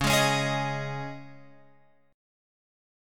C#sus2 chord {9 11 11 x 9 11} chord